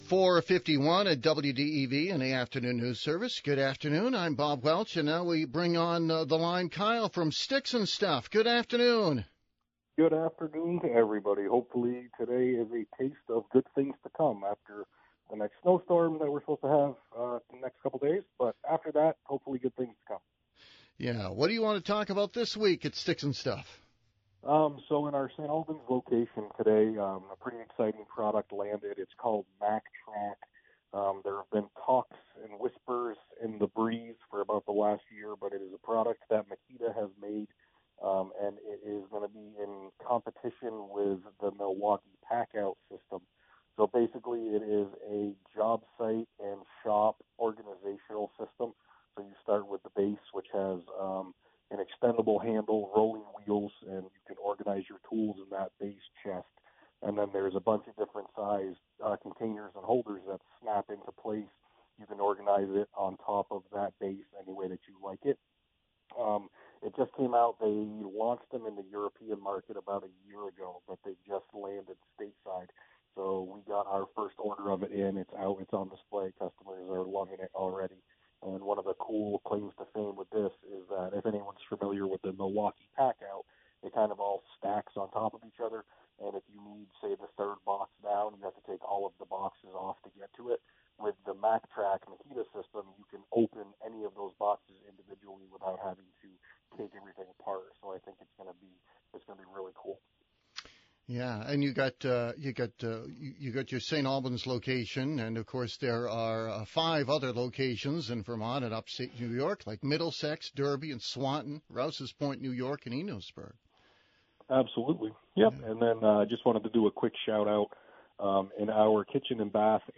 live on WDEV and Radio Vermont Group.